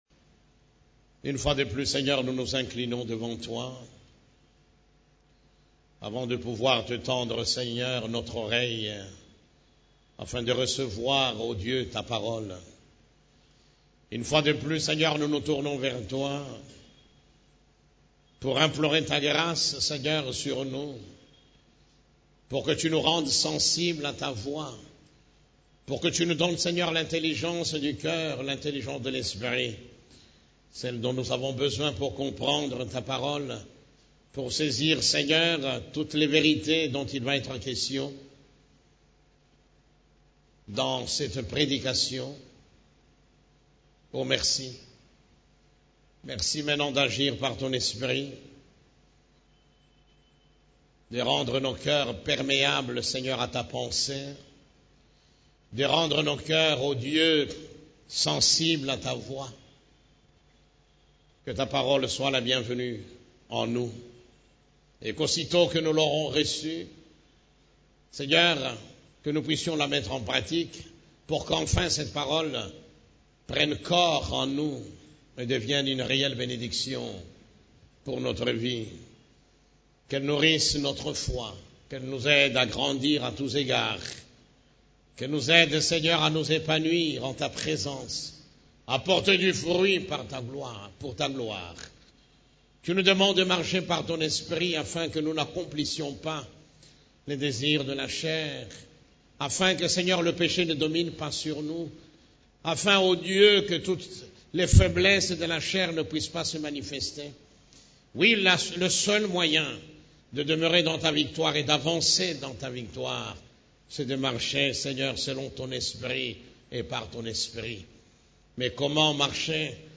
CEF la Borne, Culte du Dimanche, Créés et rachetés pour régner dans cette vie